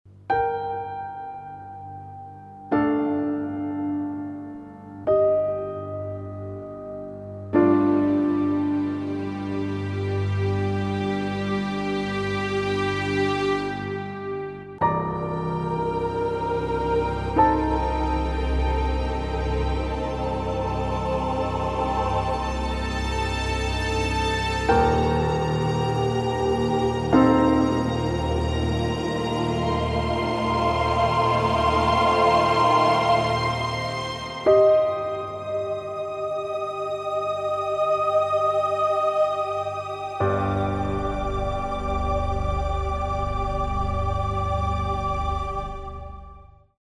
Simple Hold Music